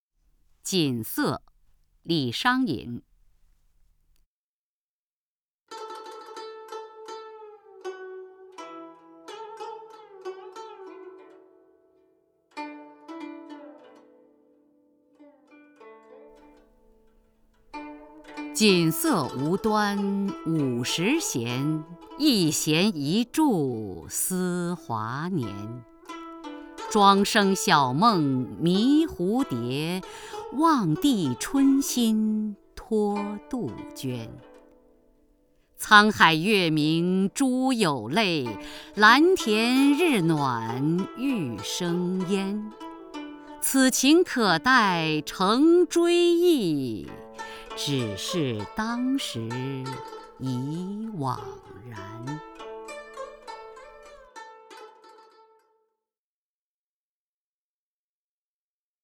首页 视听 名家朗诵欣赏 雅坤
雅坤朗诵：《锦瑟》(（唐）李商隐)